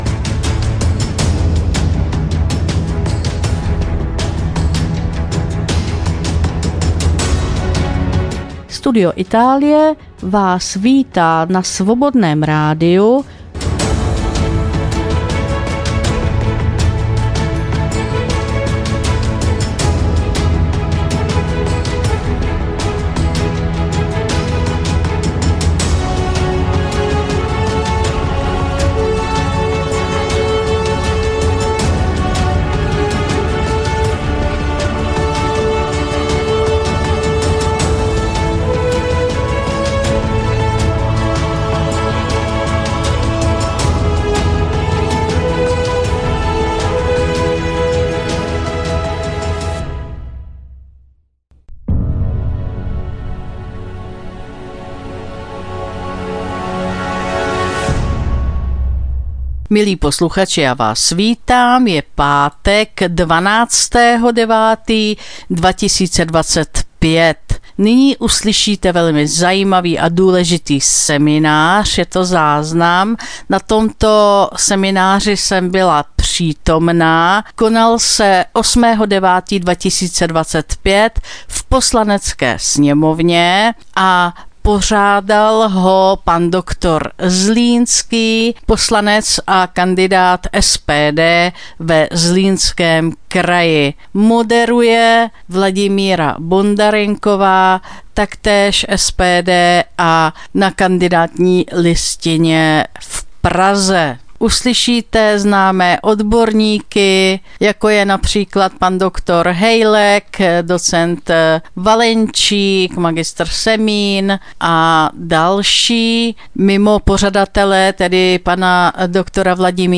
Studio Itálie – Záznam semináře: Transformace současného světa – Prognóza dalšího vývoje